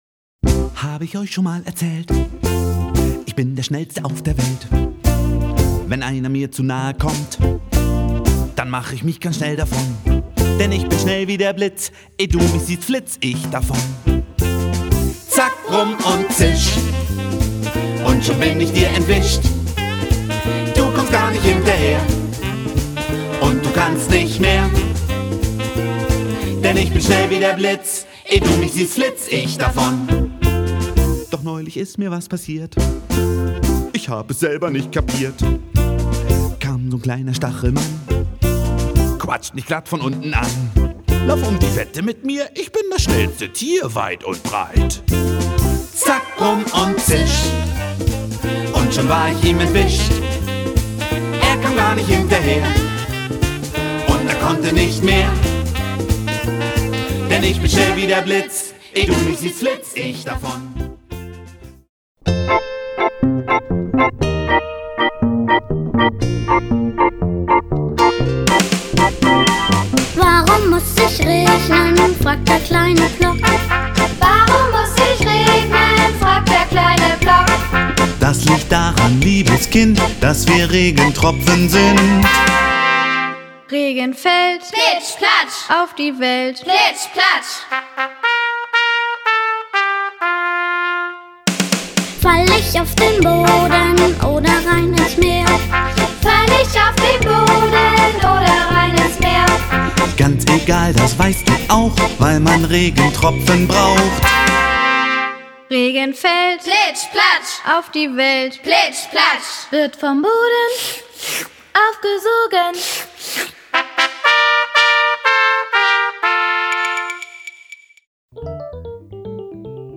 Beliebte Spiel-, Tanz- und Bewegungslieder